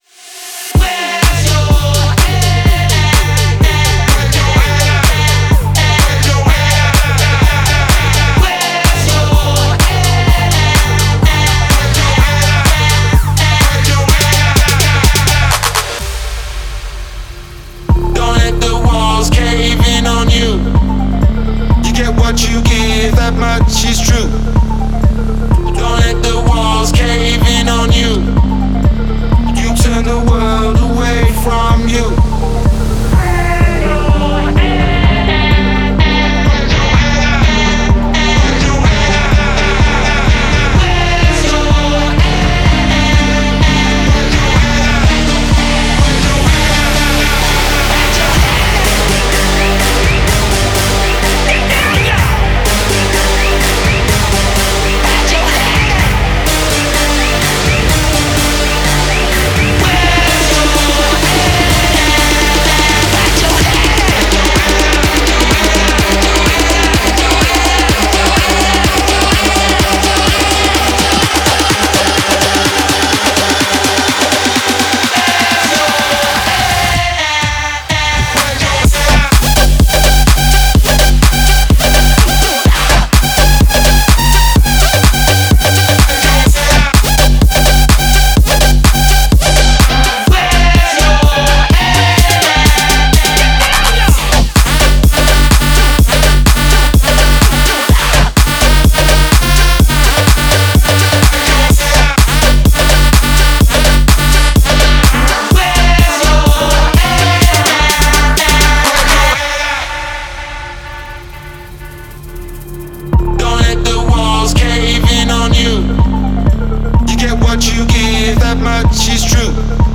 энергичная танцевальная композиция